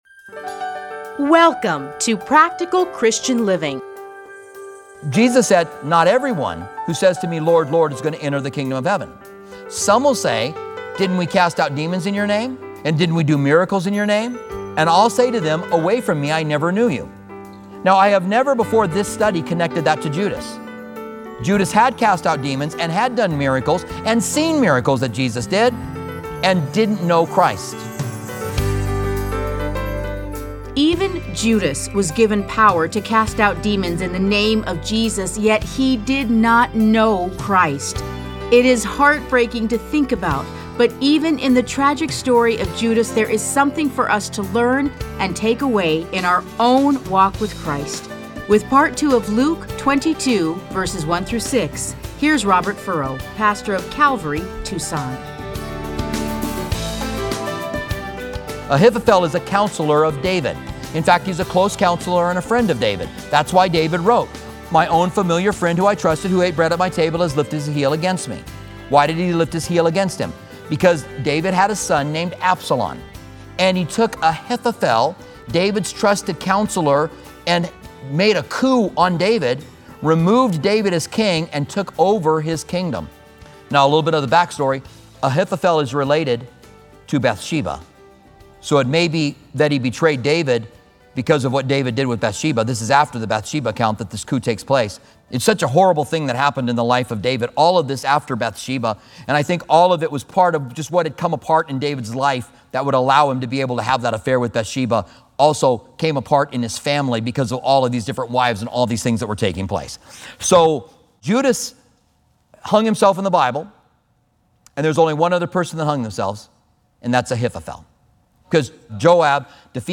Listen to a teaching from Luke 22:1-6.